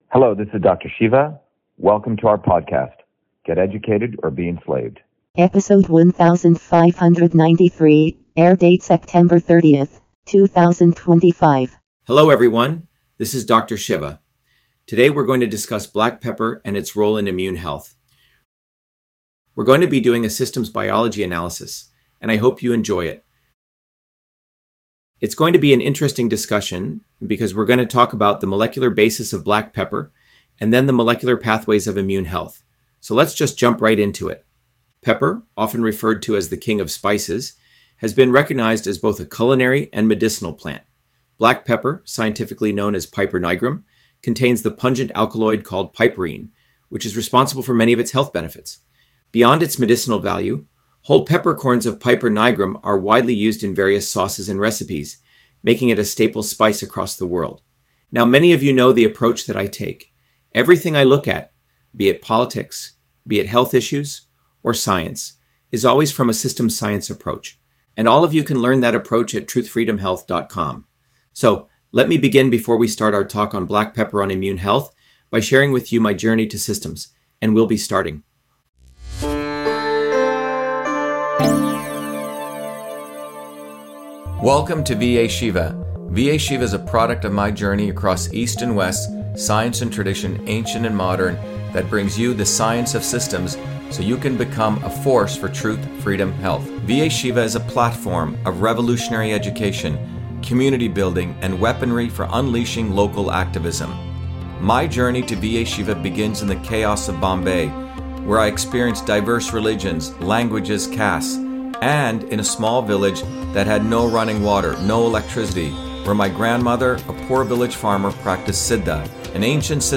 In this interview, Dr.SHIVA Ayyadurai, MIT PhD, Inventor of Email, Scientist, Engineer and Candidate for President, Talks about Black Pepper on Immune Health: A Whole Systems Approach